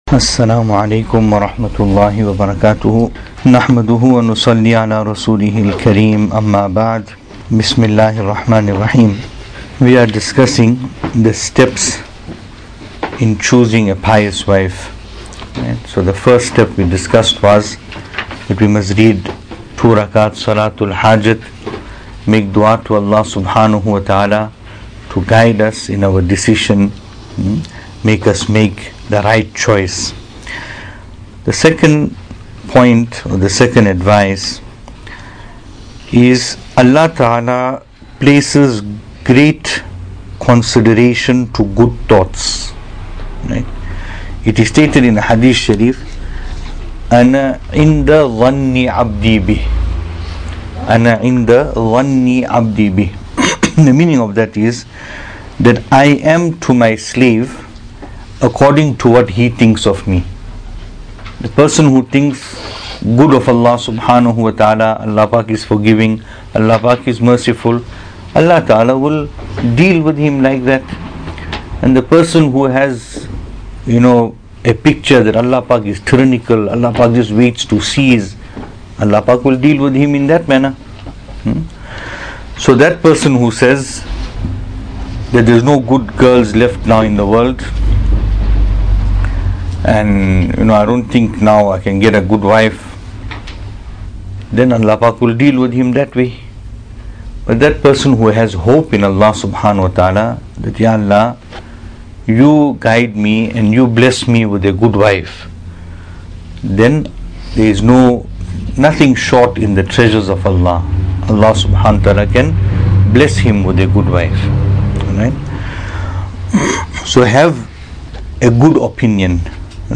Venue: MADRESSA ISHA’ATUL HAQ, Pietermaritzburg | Series: Tohfa-e-Dulha